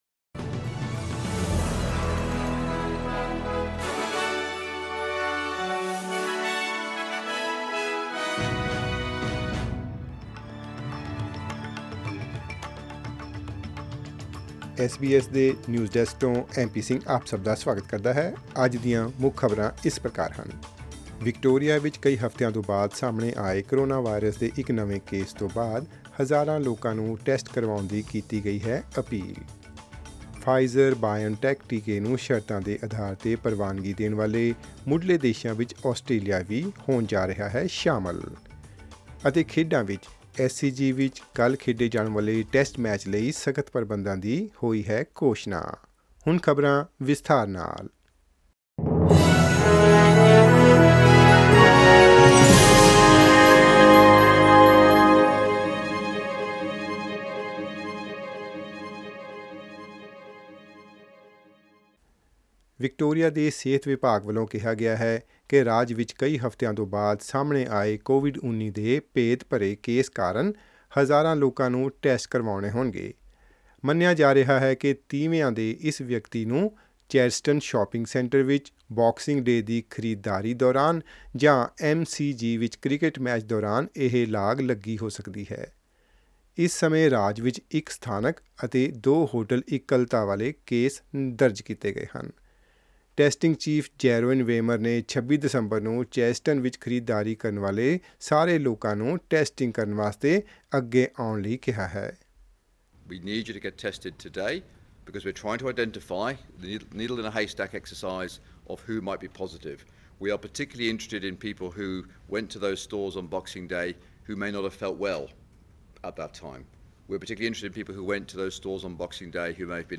SBS Punjabi News 6 Jan: Tougher restrictions announced for tomorrow's Test match at the SCG.